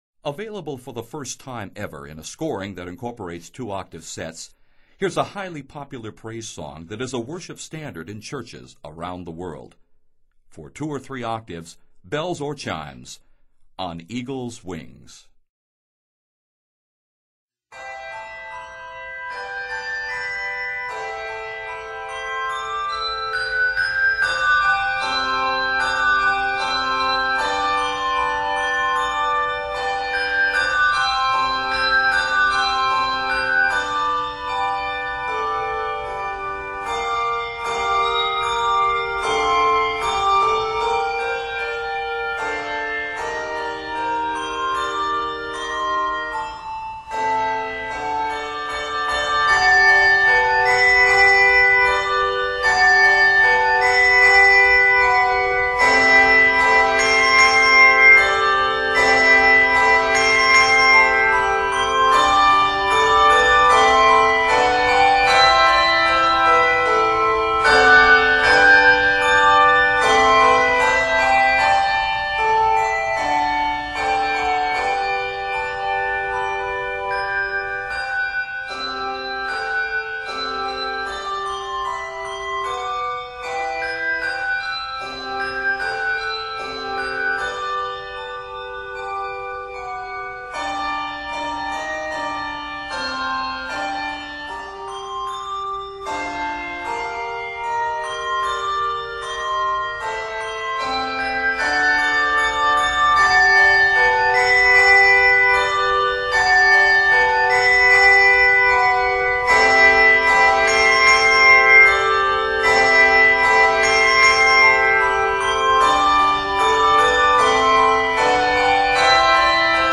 It is scored in G Major.